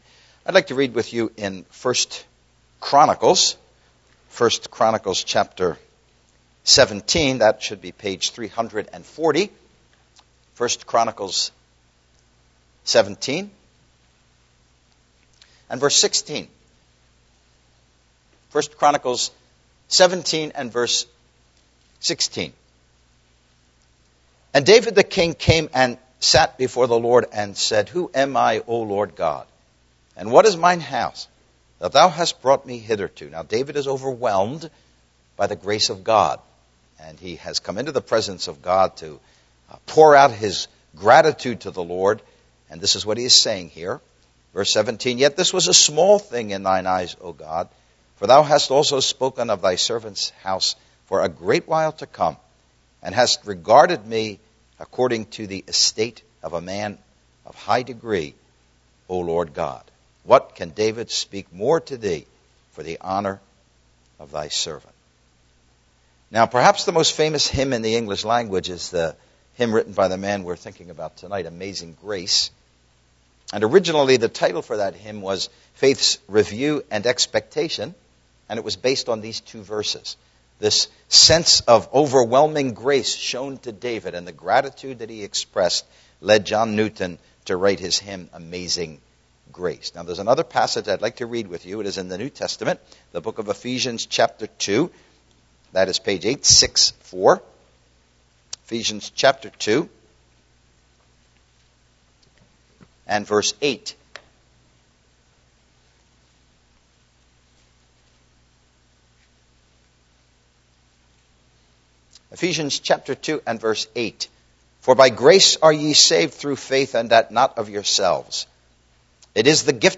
Series: The Impact of The Bible and Christianity on World History Service Type: Gospel Preaching